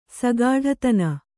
♪ sagāḍhatana